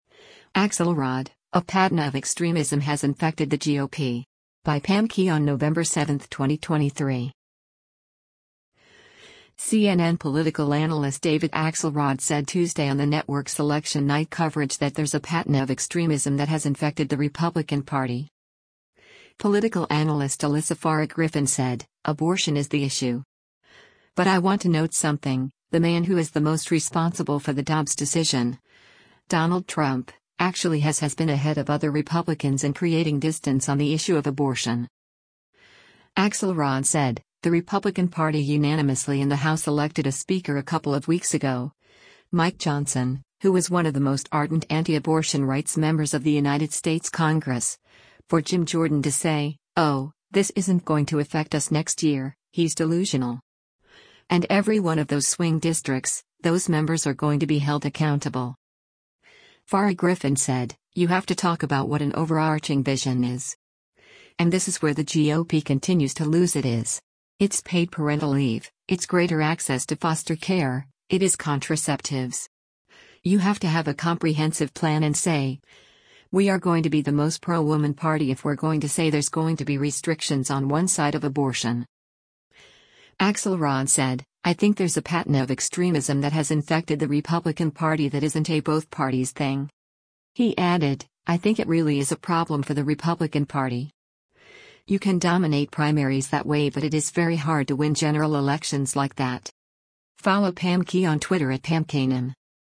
CNN political analyst David Axelrod said Tuesday on the network’s election night coverage that “there’s a patina of extremism that has infected the Republican Party.”